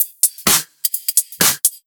Index of /VEE/VEE2 Loops 128BPM
VEE2 Electro Loop 264.wav